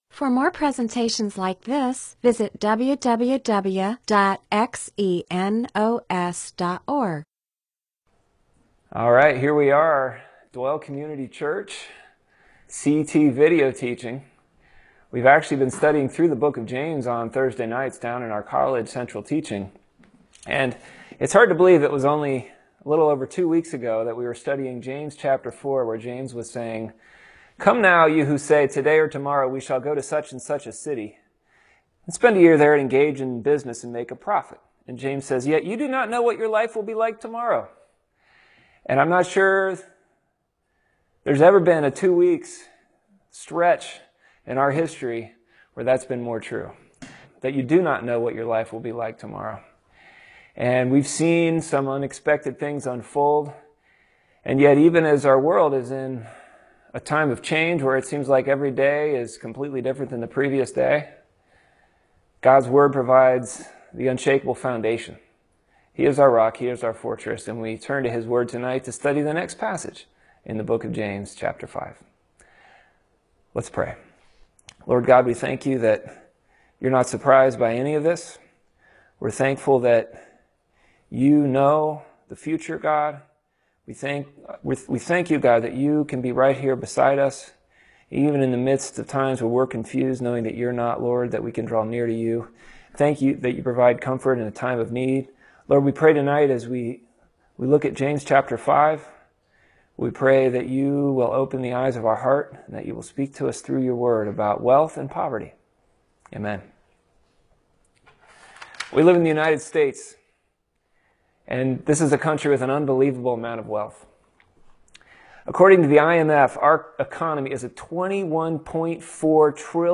Bible teaching (presentation, sermon) on James 5:1-6